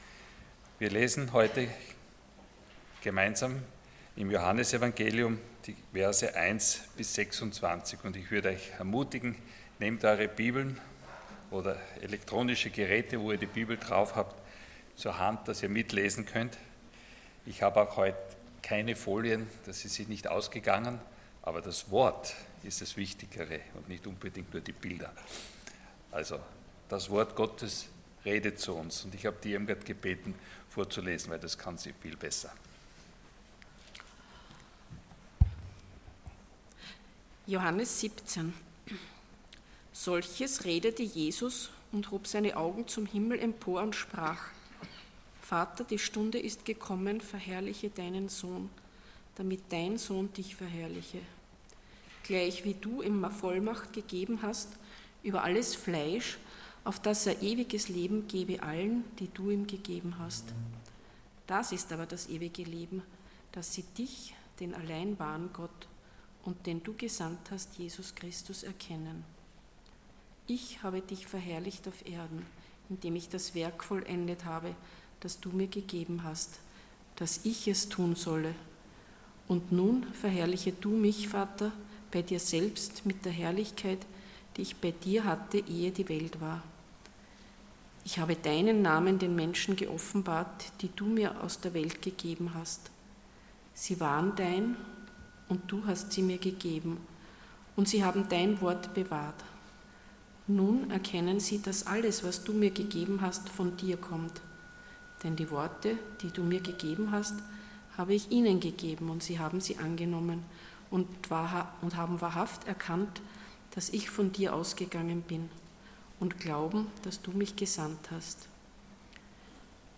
Prediger
Passage: John 17:1-26 Dienstart: Sonntag Morgen